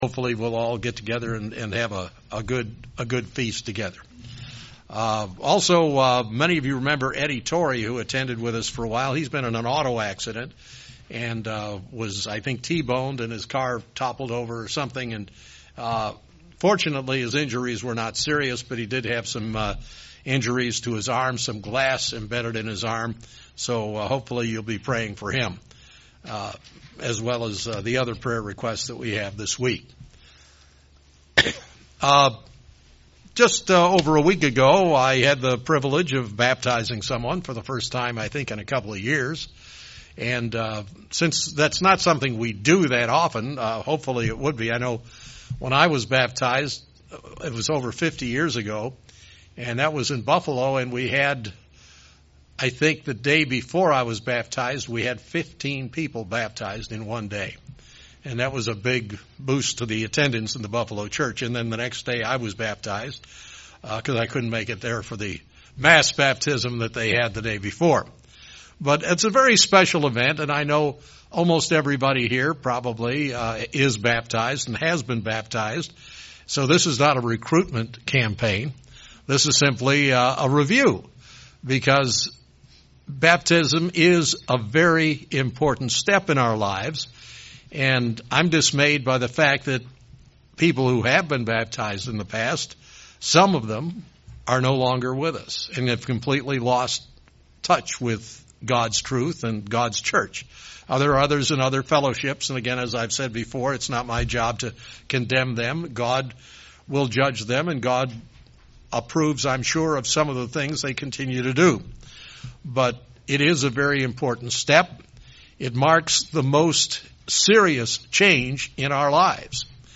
A sermon looking into the biblical topic of Baptism.